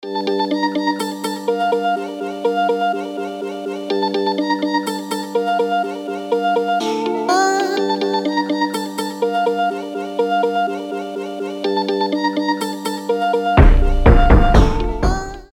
Хип-хоп
без слов